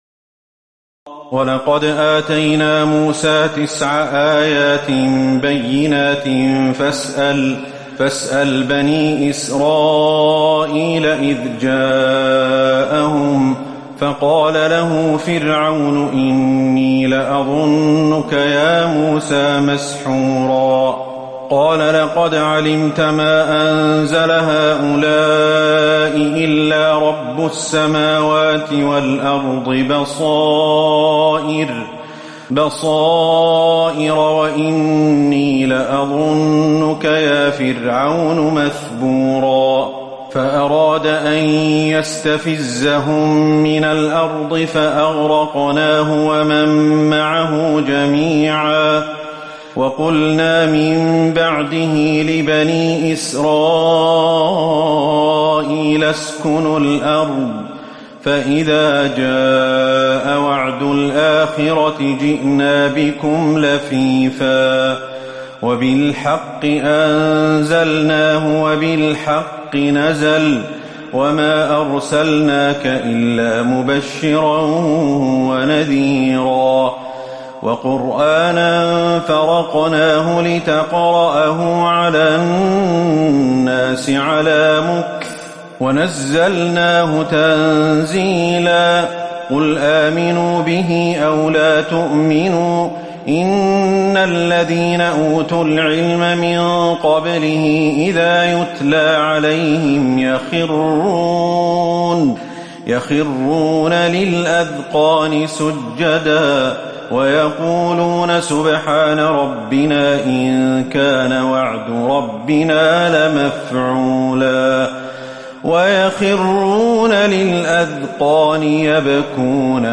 تراويح الليلة الخامسة عشر رمضان 1438هـ من سورتي الإسراء (101-111) والكهف (1-82) Taraweeh 15 st night Ramadan 1438H from Surah Al-Israa and Al-Kahf > تراويح الحرم النبوي عام 1438 🕌 > التراويح - تلاوات الحرمين